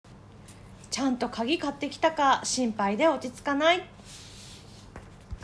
そこで、読者の皆様によりリアルに「はこだて弁」を体感していただきたく、全ての例文の音声収録をしました！